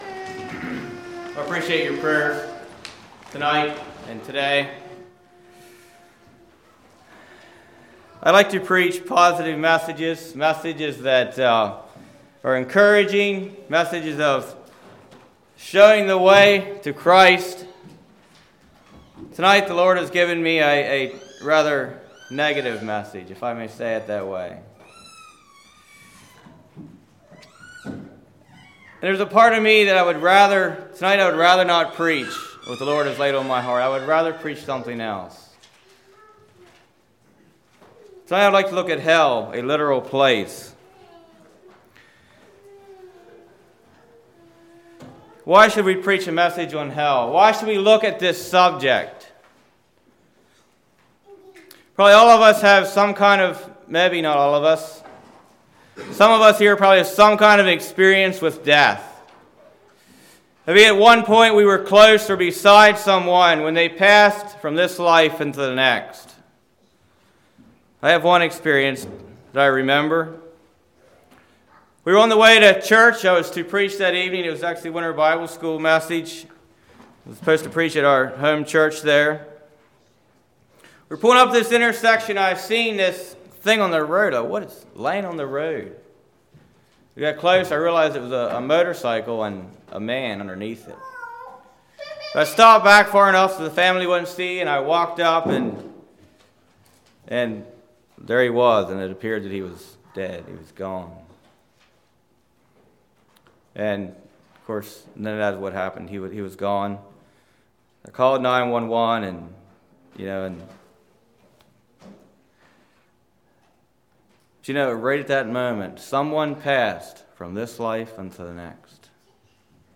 A Literal Place Congregation: Lake View Speaker
2016 Sermon ID